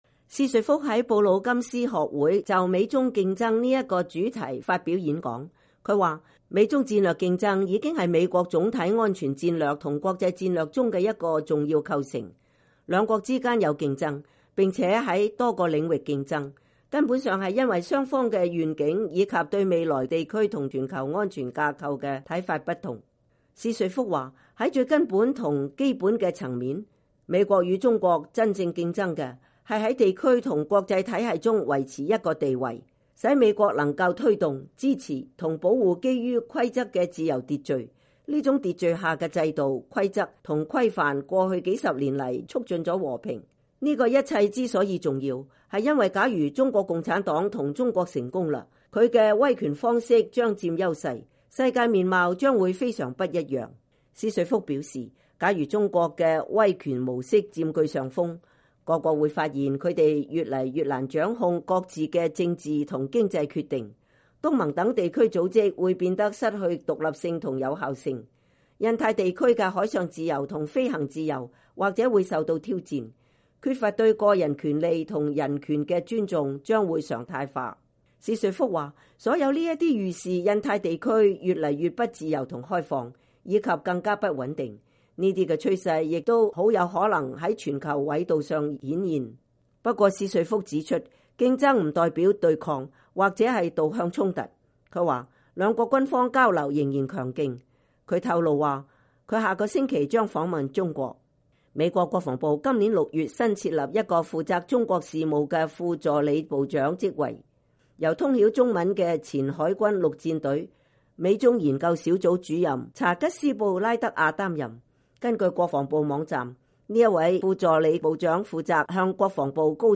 美國國防部印太安全事務助理部長薛瑞福2019年10月1日在華盛頓智庫布魯金斯學會發表演講。